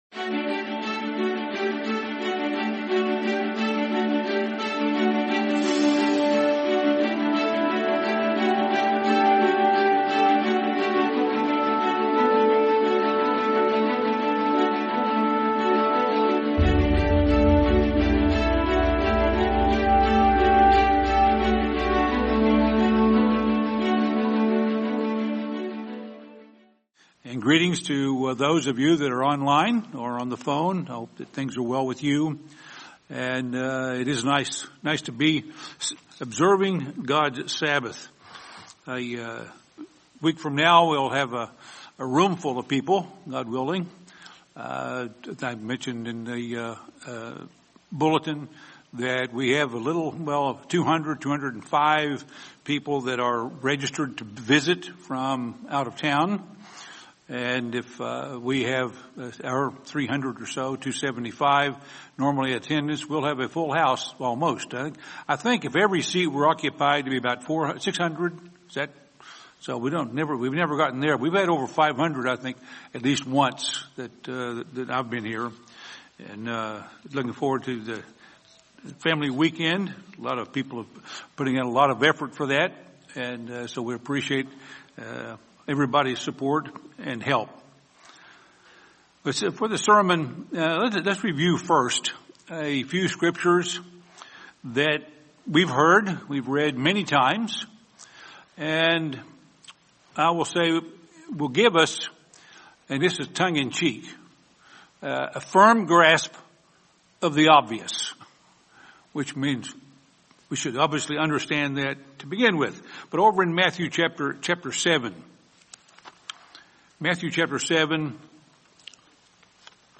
Beware of Deceivers and Hold Firmly to the Truth | Sermon | LCG Members